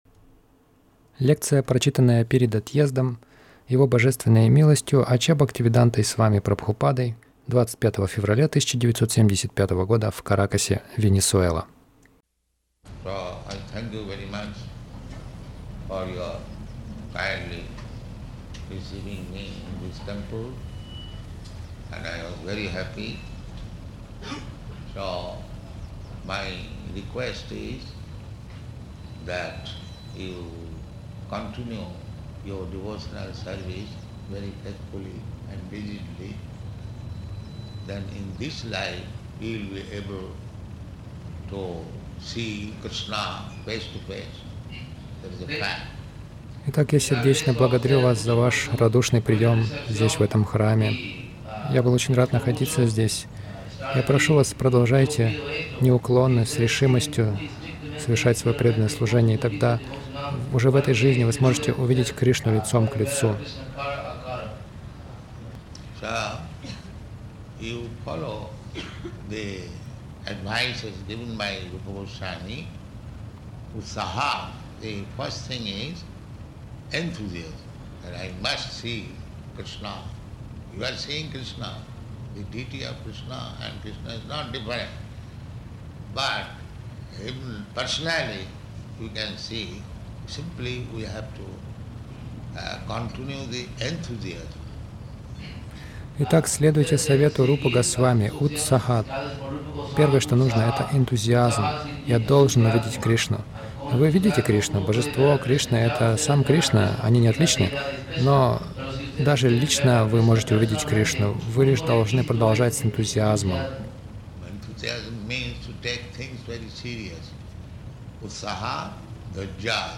Лекция — Перед отъездом по Нектару Наставлений
Милость Прабхупады Аудиолекции и книги 25.02.1975 Лекции | Каракас Лекция — Перед отъездом по Нектару Наставлений Загрузка...